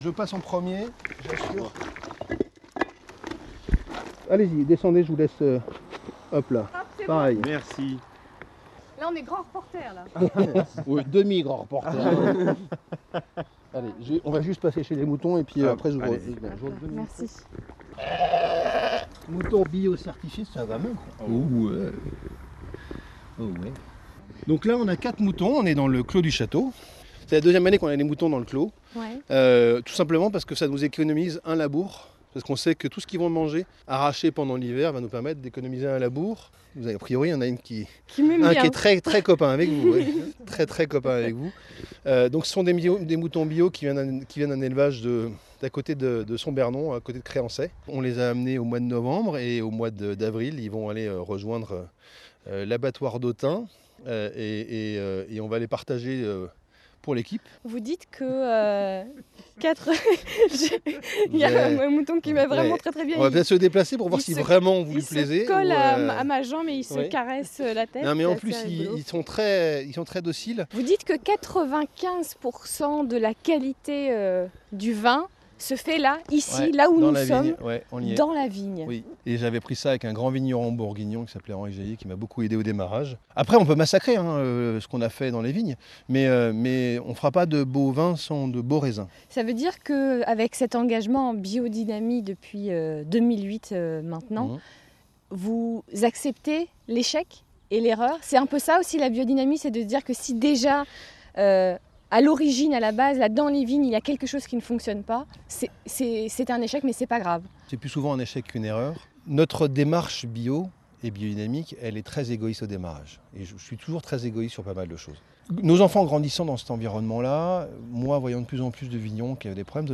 Vendredi 25 février 2022, nous accueillions France Bleu Bourgogne au Domaine : un reportage à écouter pour découvrir notre nouveau projet…
Sur le chantier